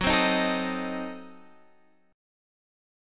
F#dim7 chord